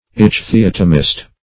Ichthyotomist \Ich`thy*ot"o*mist\, n. One skilled in ichthyotomy.